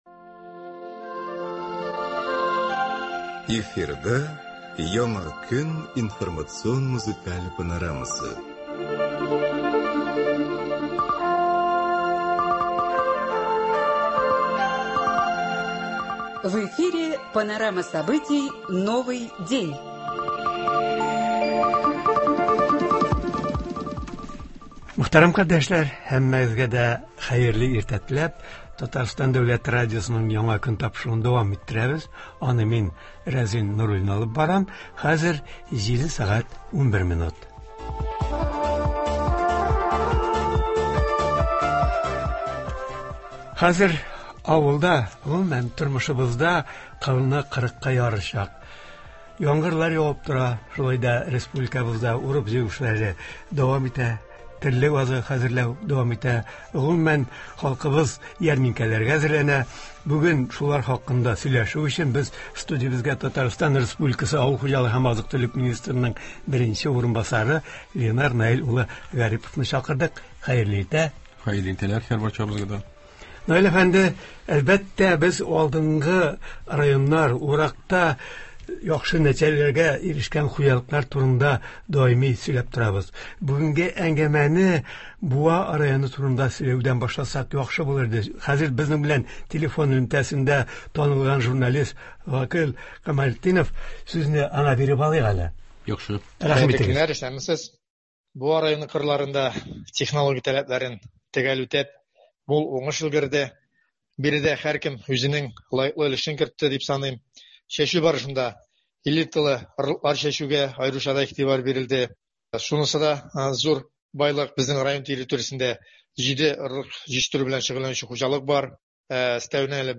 Тапшыруда Татарстан республикасы авыл хуҗалыгы һәм азык-төлек министрының беренче урынбасары Ленар Наил улы Гарипов катнашачак, уракның барышы, алдынгы районнар, шулай ук терлекчелекнең кышлатуга әзерлеге турында сөйләячәк, сентябрь ахырында үткәреләчәк авыл хуҗалыгы ярминкәләре, аларның кайсы төбәкләрдә үткәреләчәге, кайсы районнар катнашып, нинди товарлар алып киләчәкләре турында сөйләячәк, тыңлаучылар сорауларына җавап бирәчәк.